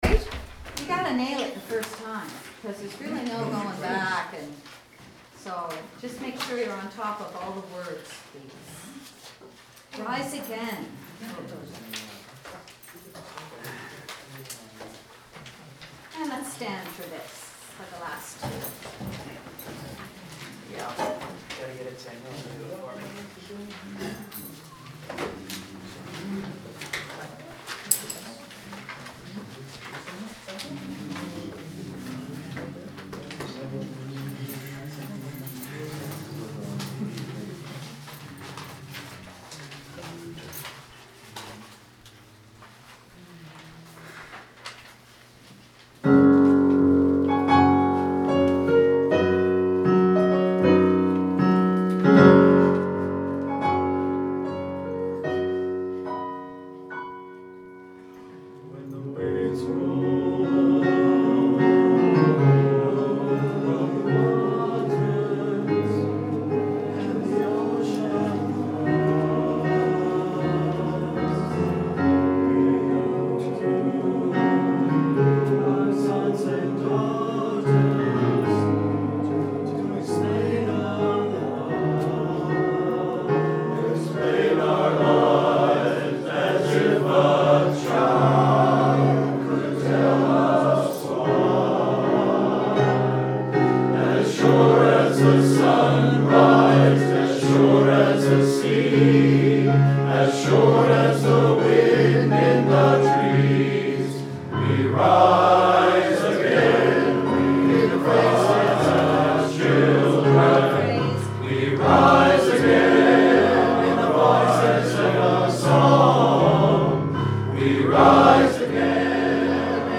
Coastal Voices Men's Choir